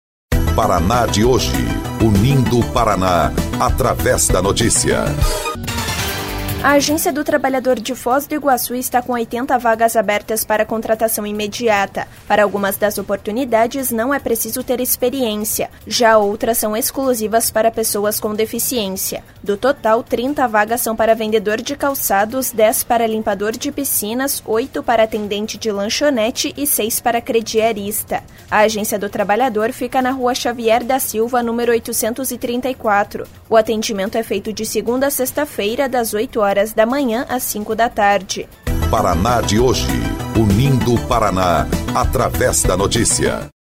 BOLETIM – Agência do Trabalhador de Foz do Iguaçu oferece 80 vagas